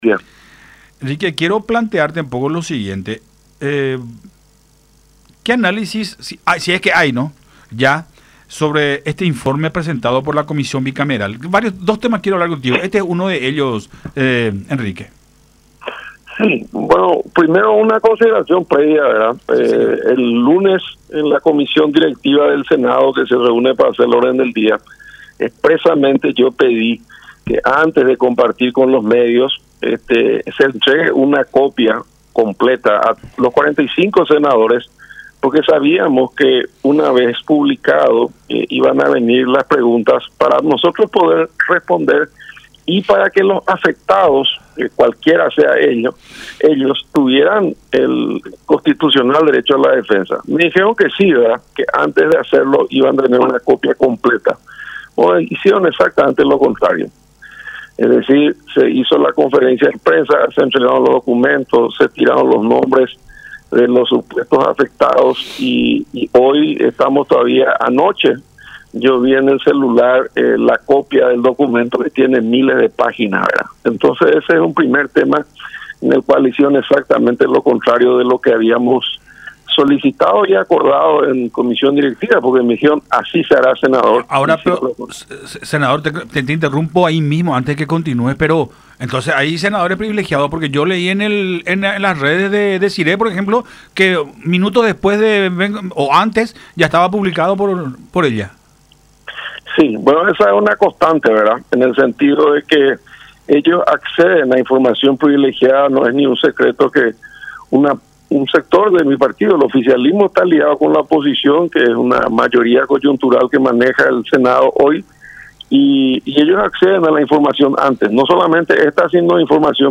Así, si se hacen números, hace diez meses se está con este asedio político y mediático en plena campaña”, expuso Riera en conversación con Nuestra Mañana por Unión TV radio La Unión.